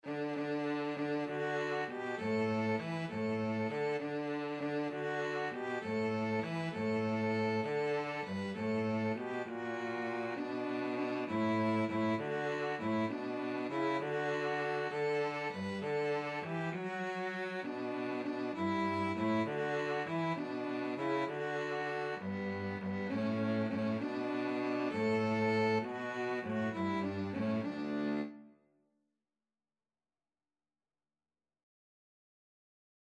In Dulci Jubilo Free Sheet music for Violin-Cello Duet
Info: In dulci jubilo ("In sweet rejoicing") is a traditional Christmas carol.